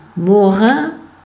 Morens (French: [mɔʁɑ̃s], Franco-Provençal: [moˈʁɛ̃]
Frp-greverin-Morin.ogg.mp3